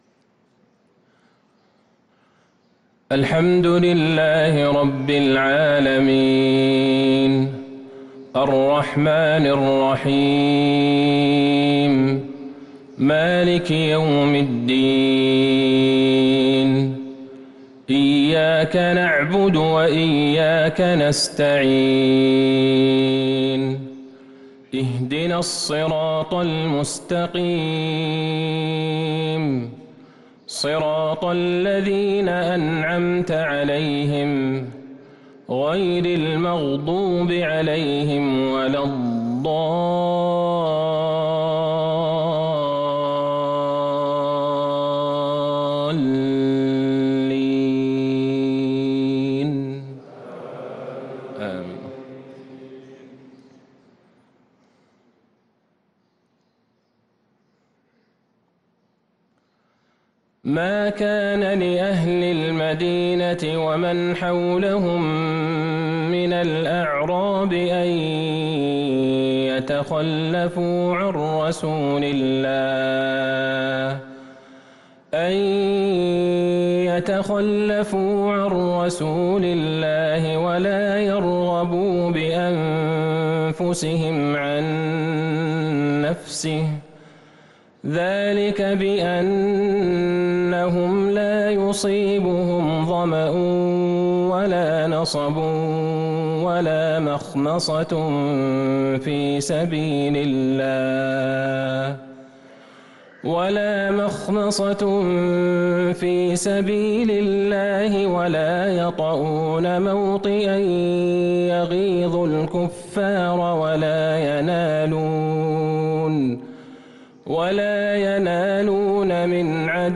صلاة الفجر للقارئ عبدالله البعيجان 25 رمضان 1443 هـ